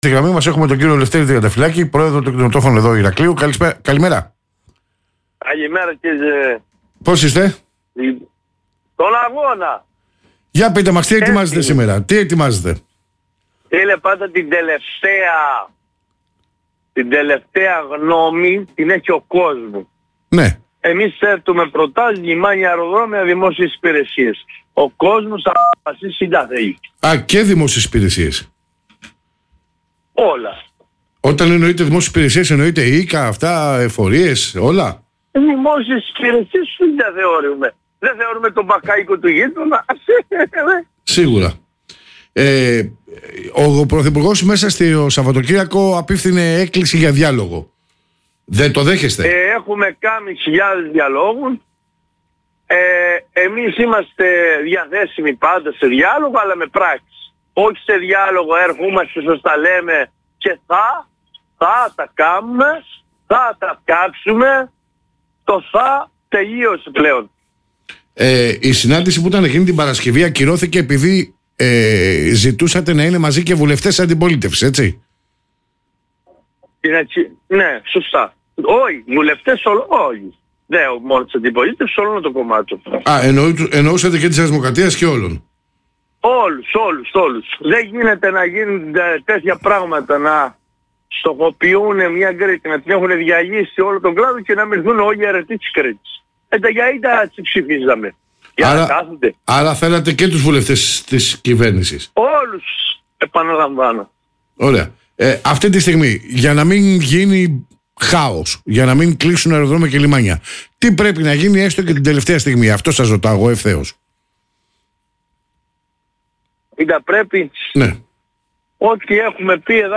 Στην εκπομπή «ΟΛΑ ΜΑΖΙ»